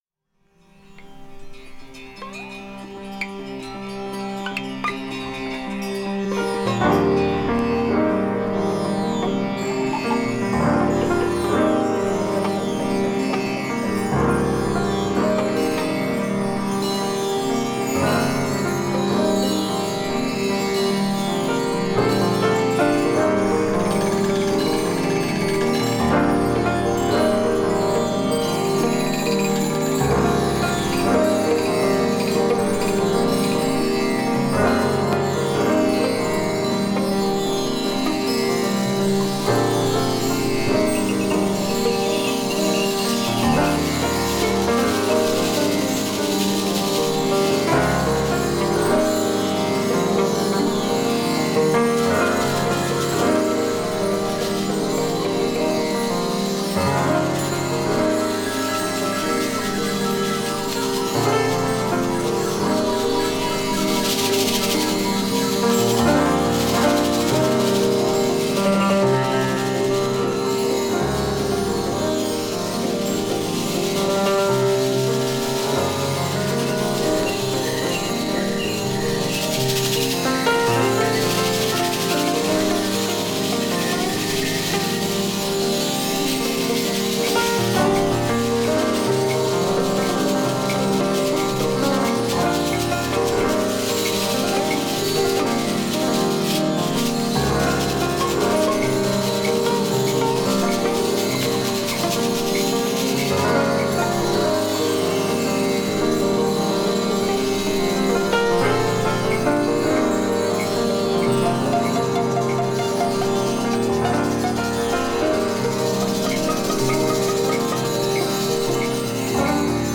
all vinyl spiritual jazz mix
consisting of mostly jazz from the late 60s and early 70s